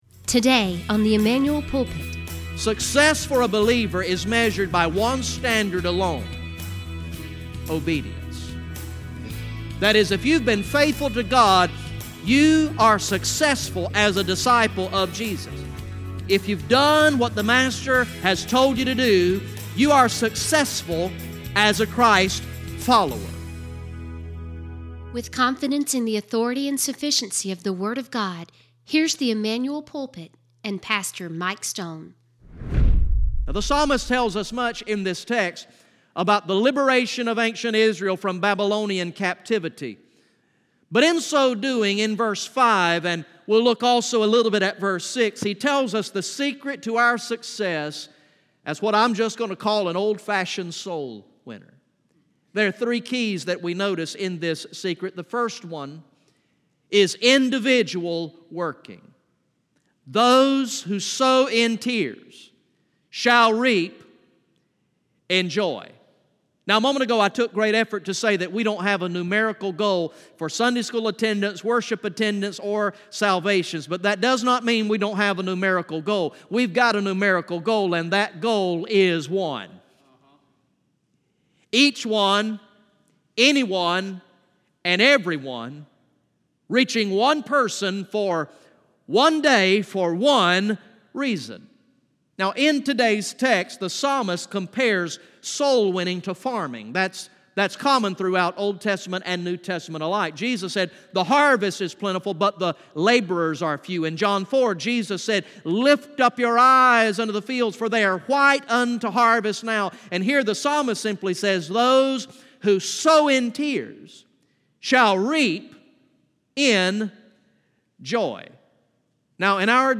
From the morning worship service on Sunday, September 30, 2018